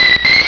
Cri de Rémoraid dans Pokémon Rubis et Saphir.